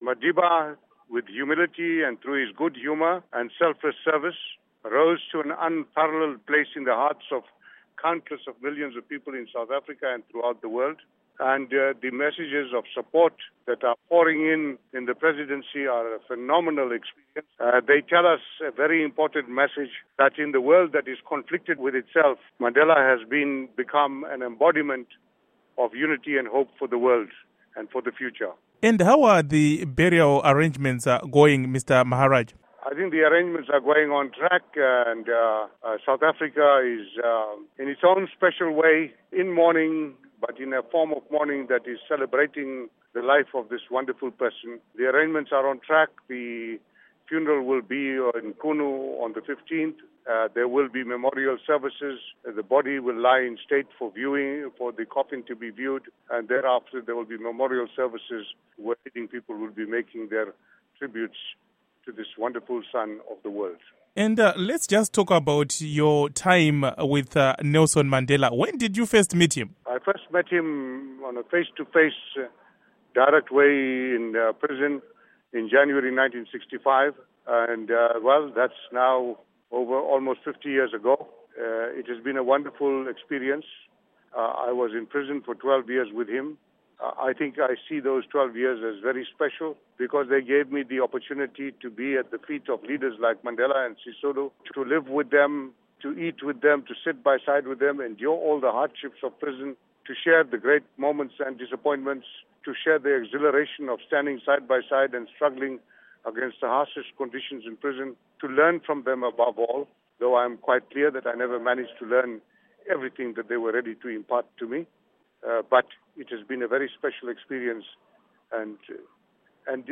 Interview With Mac Maharaj on Mandela